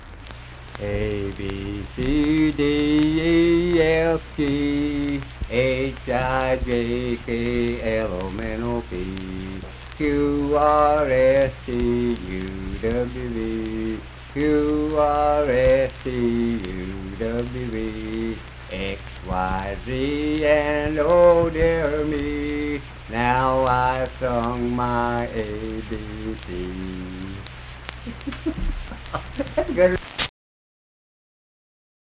Unaccompanied vocal and banjo music
Verse-refrain 1(6).
Voice (sung)